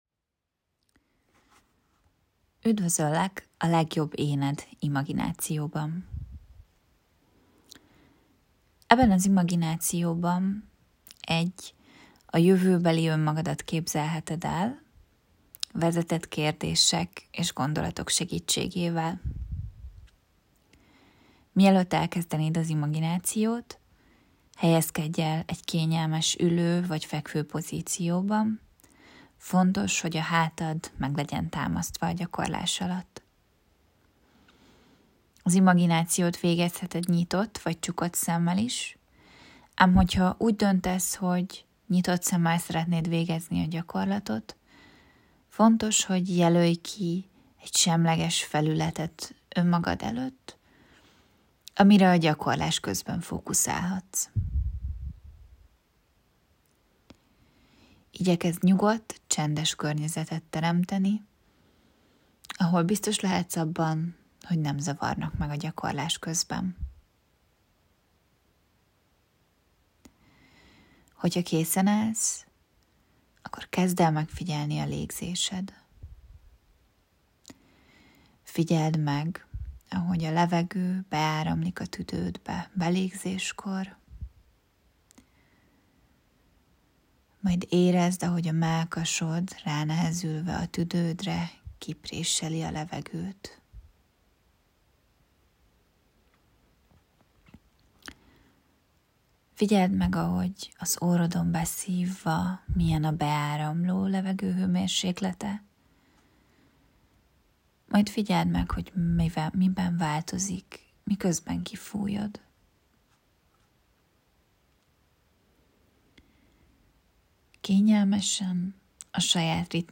👇 Felteszem a kérdéseim Vizualizációs hanganyag